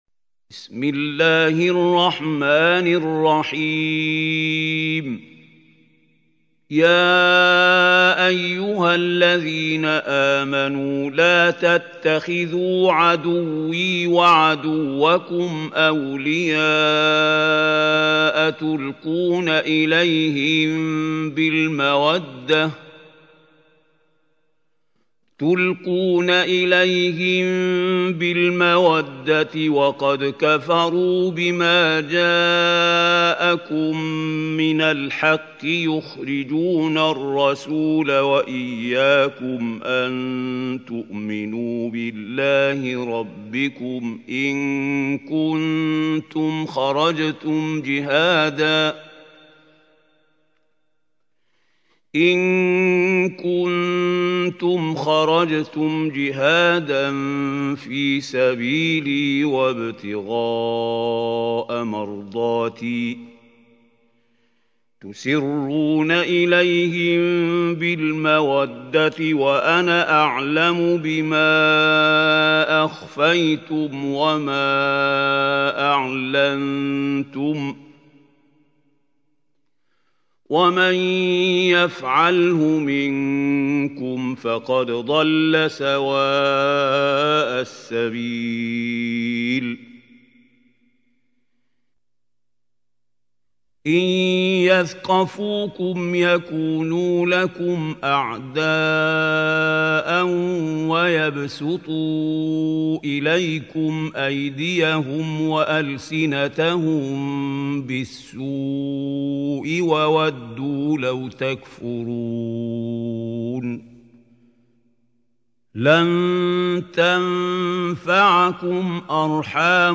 سورة الممتحنة | القارئ محمود خليل الحصري